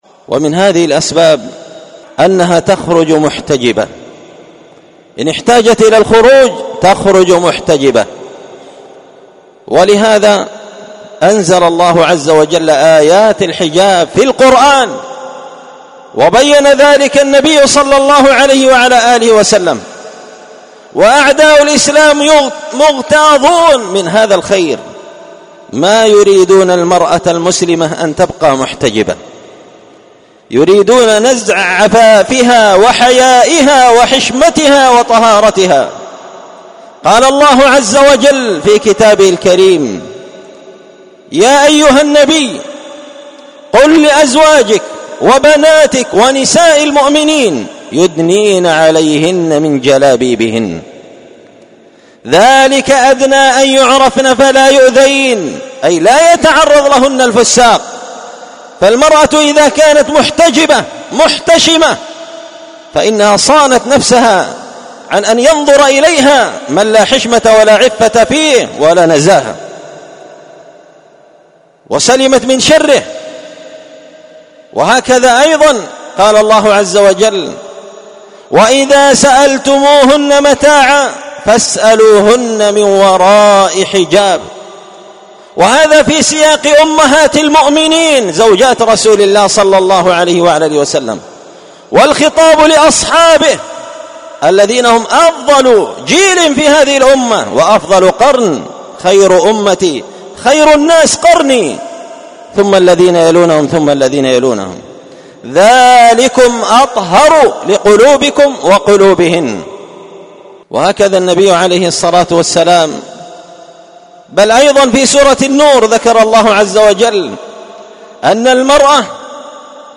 سلسلة مقتطفات من خطبة جمعة بعنوان حراسة الفضيلة وحماية المجتمع من الرذيلة ⏸المقتطف الخامس⏸السبب الثالث من الأسباب التي تحرس وتحمى بها الفضيلة خروج المرأة بالحجاب
دار الحديث بمسجد الفرقان ـ قشن ـ المهرة ـ اليمن